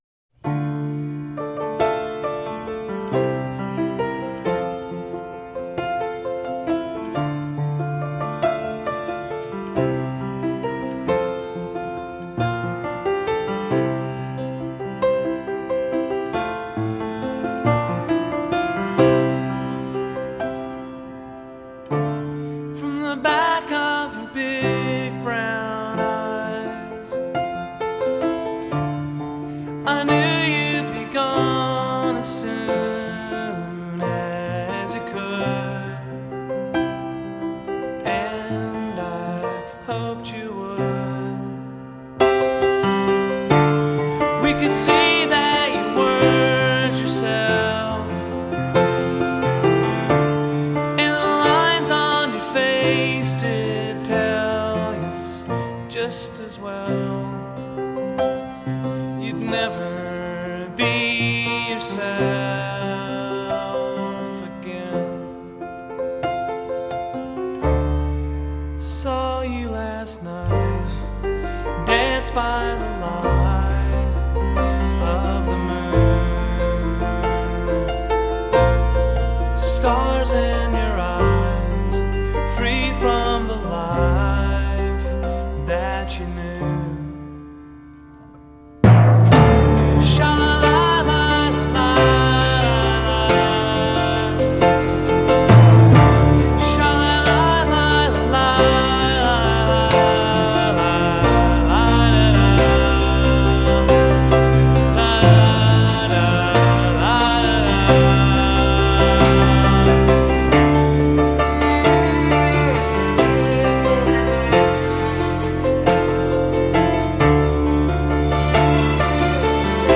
Another sad, gorgeous, and lushly orchestrated melody